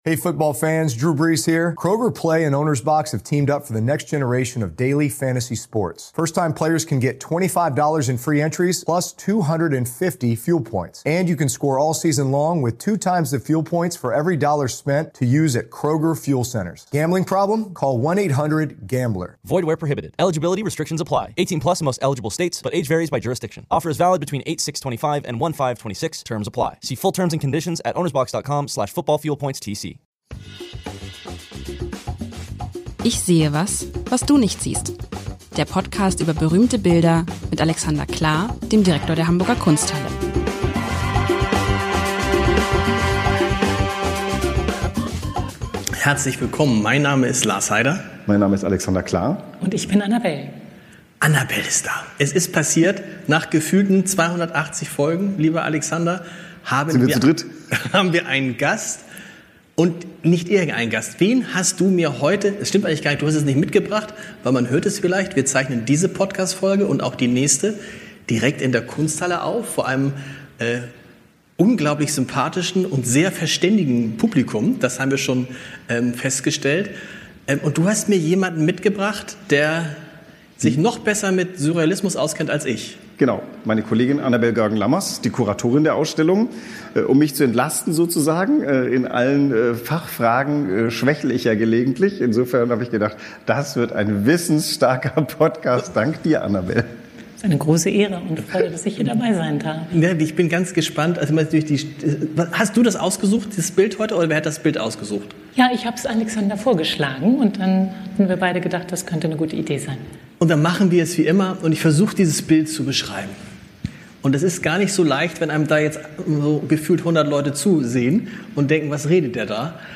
Heute geht es um das Bild „Traum“ von Toyen aus dem Jahr 1937. Live-Mitschnitt vor Publikum aus der Kunsthalle mit einem besonderen Gast.